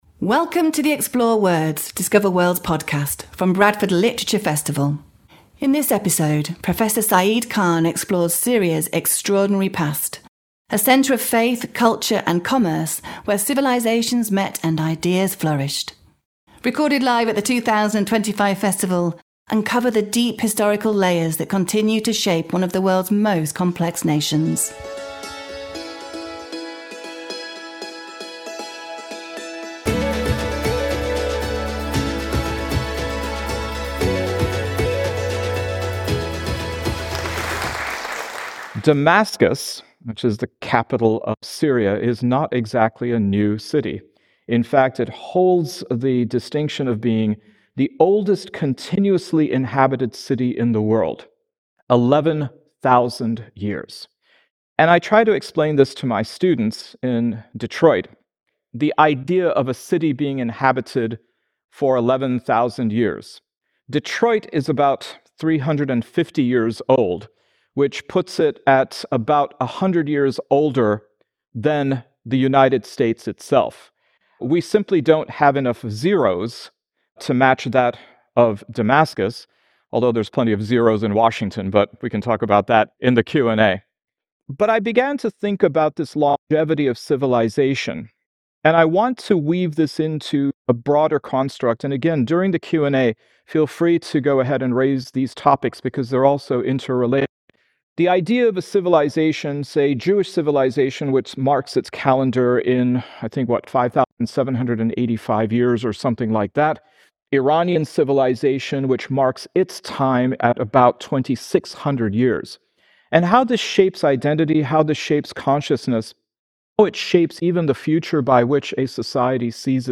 This talk explores Syria’s extraordinary past: a centre of faith, culture, and commerce where civilisations met and ideas flourished. As we trace its path through colonialism and independence to the early years of the Assad regime, we uncover the deep historical layers that continue to shape one of the world’s most complex nations.